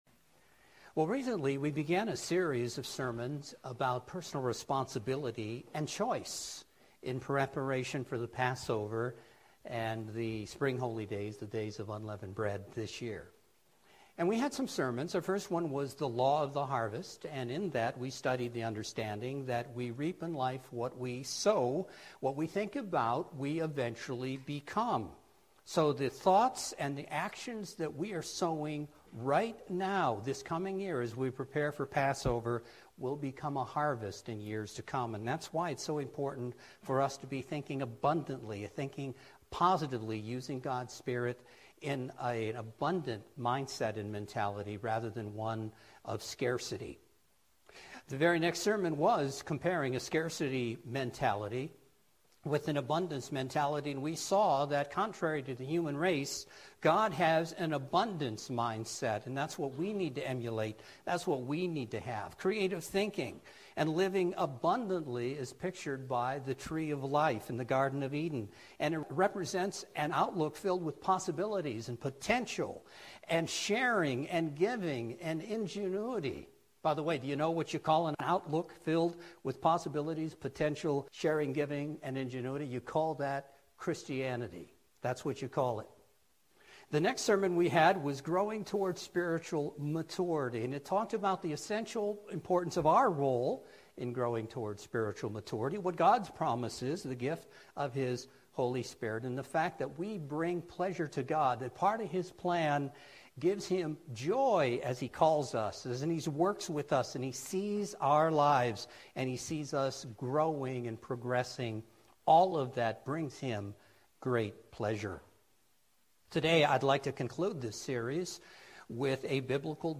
Today we will conclude a four-part series of Sermons with a biblical discussion on the final end-result of a fruitful harvest, an abundant mindset and spiritual maturity... the demonstration of good works! The righteous things we do… honor God, help us to develop the mind of Christ, and prepare us for an eternity of service.